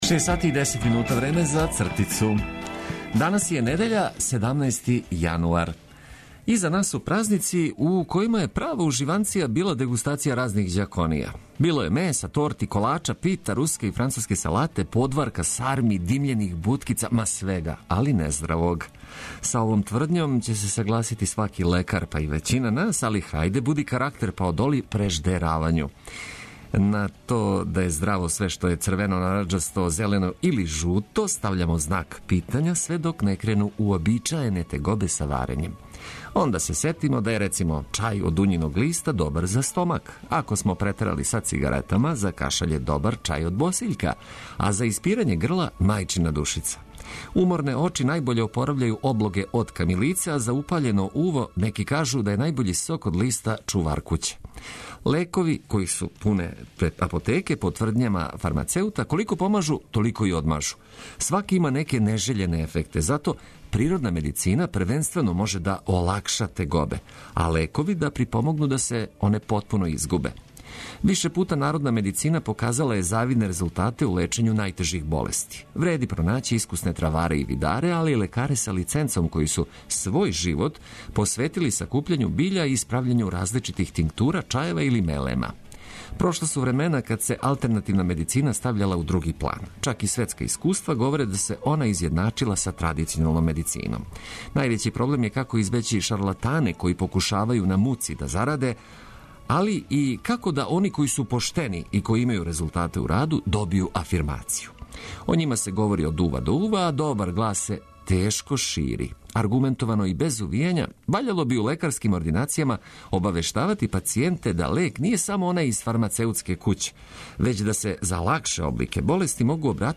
Током емисије емитоваћемо занимљиве прилоге из претходне седмице, ослушкивати најбољу музику за буђење и потрудити се да вам информацијама из земље и света, затим сервисним и спортским вестима оплеменимо недељно јутро.